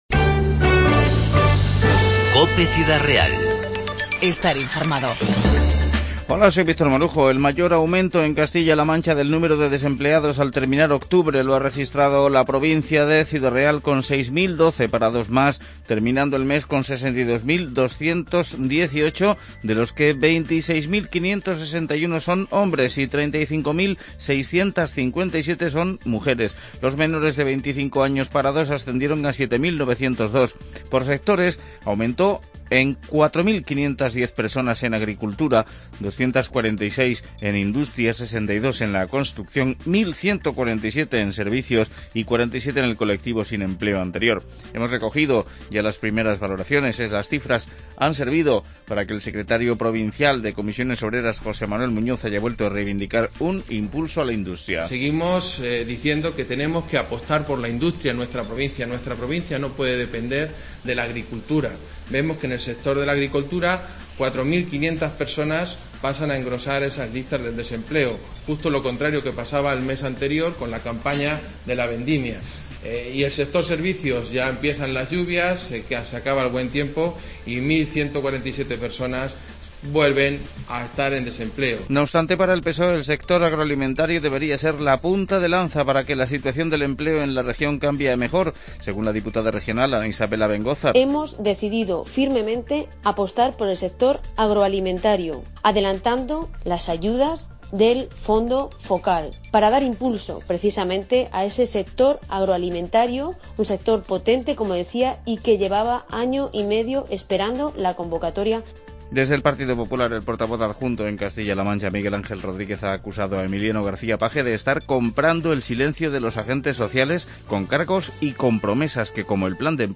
INFORMATIVO 3-11-15